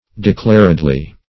declaredly - definition of declaredly - synonyms, pronunciation, spelling from Free Dictionary Search Result for " declaredly" : The Collaborative International Dictionary of English v.0.48: Declaredly \De*clar"ed*ly\, adv. Avowedly; explicitly.